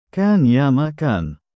♪ 音声サンプル：下の「口語発音1」に相当する読み方です
口語的発音1：كَانْ يَامَا كَانْ / كَانْ يَا مَا كَانْ [ kān yā-ma kān ] [ カーン・ヤーマ・カーン ]